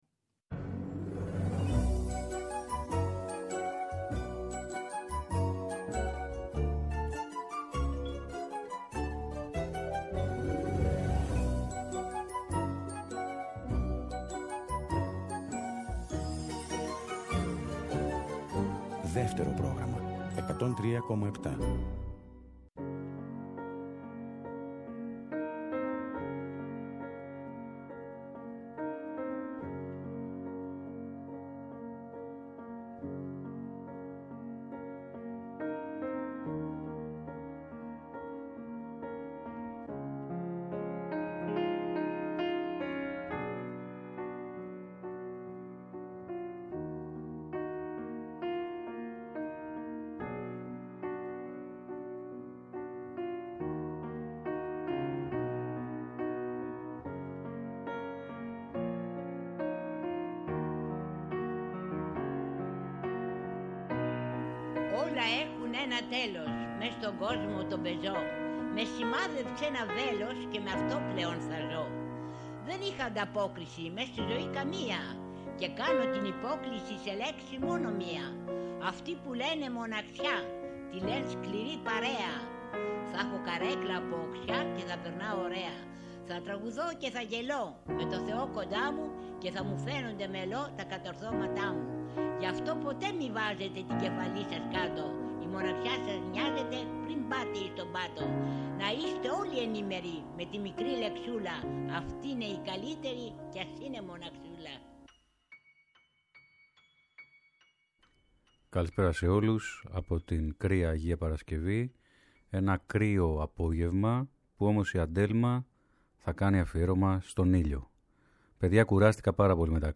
Τραγούδια ελληνικά ηλιόλουστα στο Δεύτερο Πρόγραμμα 103.7, αυτό το Σάββατο, 19 Μαρτίου 2022, στην “Αντέλμα”, στις 5 το απόγευμα ακριβώς!